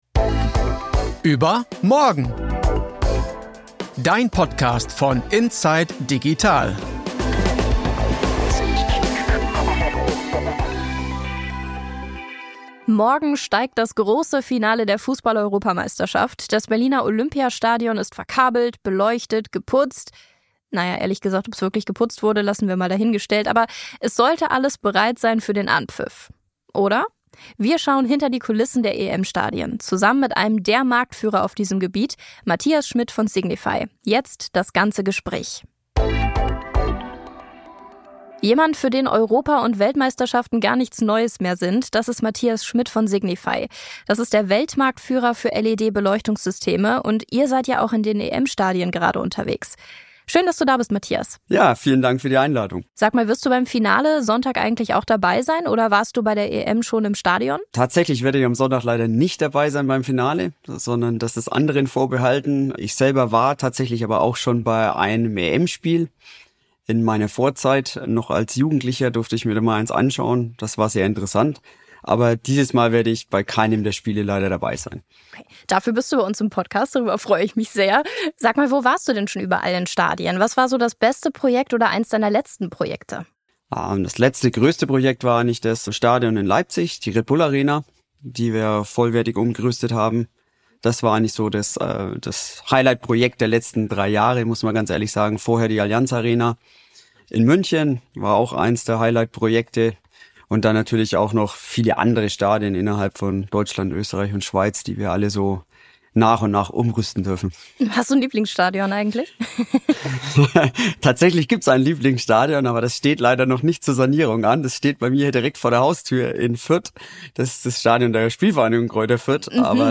Das hört ihr im ganzen Gespräch.